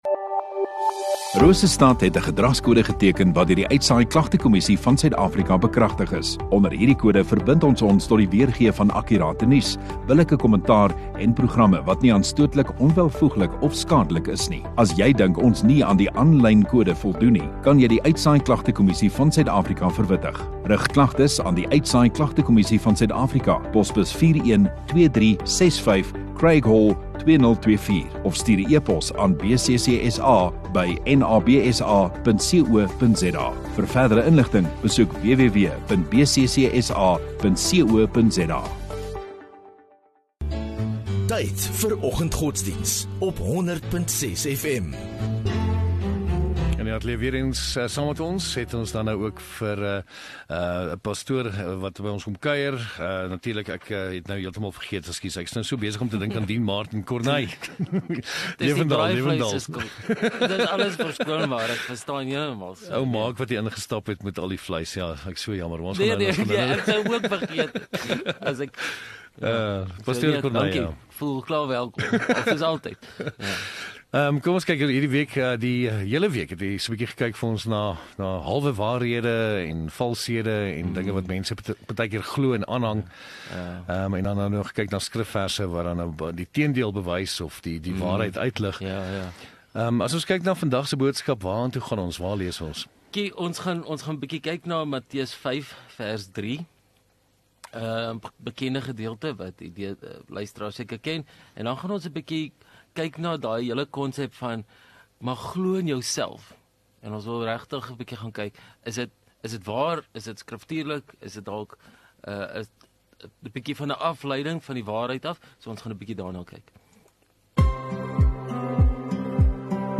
12 Apr Vrydag Oggenddiens